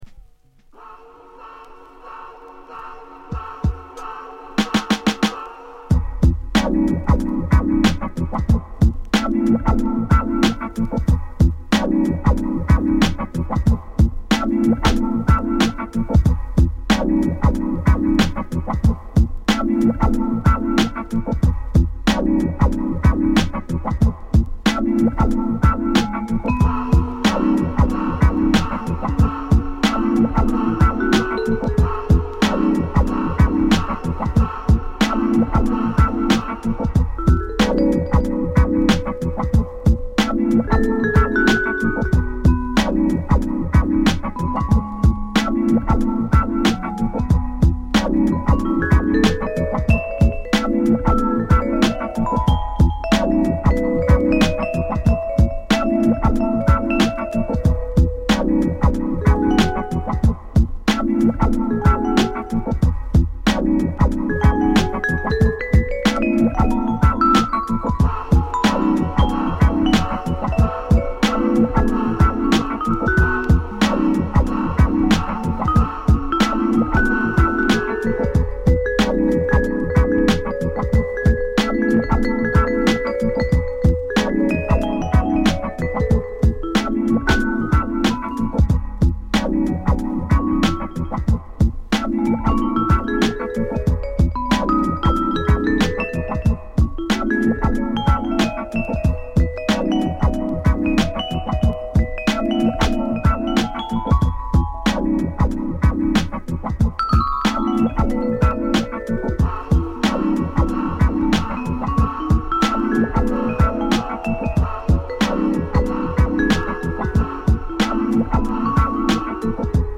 フィルター処理されたキーボードと涼し気なVibraphoneの音色が気持ち良いグルーヴのA4
同テイストのビーツに女性ヴォーカルをフィーチャーしたA5
のようなメロウスムースチューンから